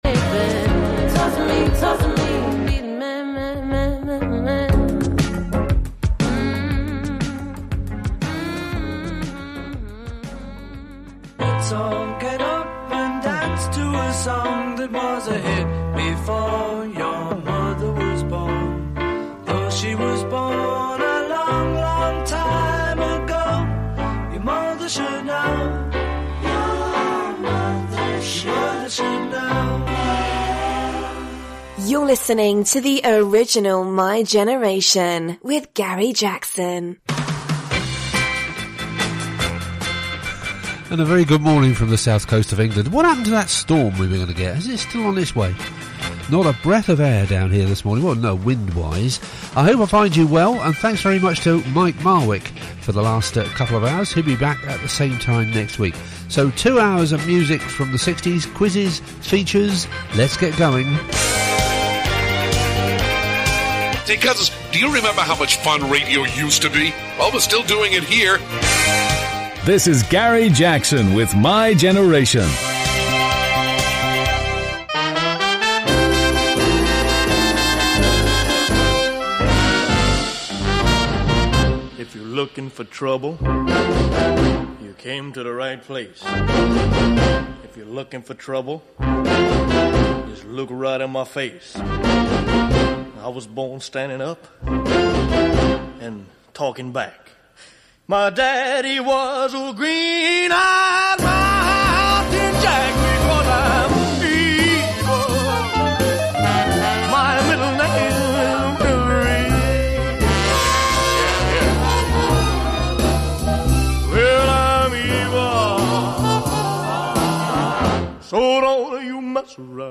60s music show